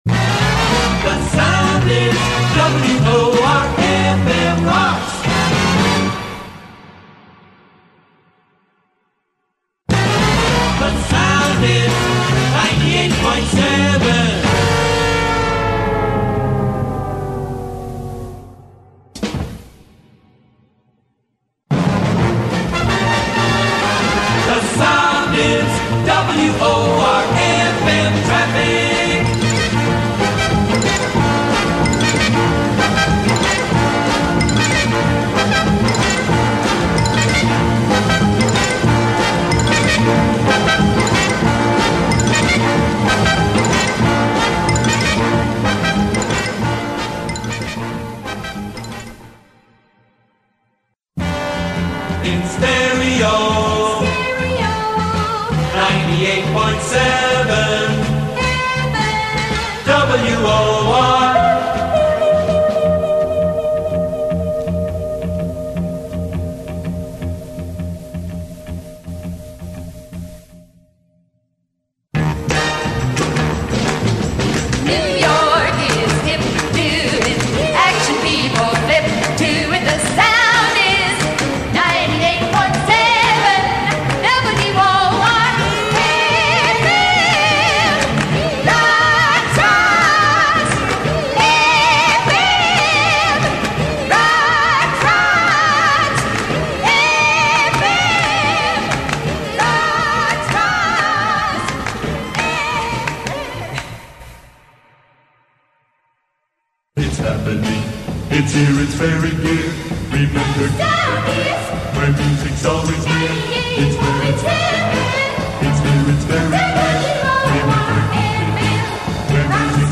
The singing was added in Dallas: